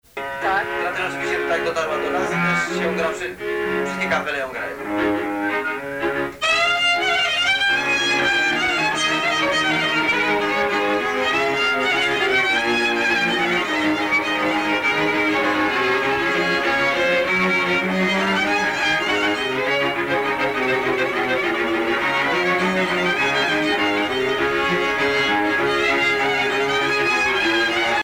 orchestre
Pièce musicale inédite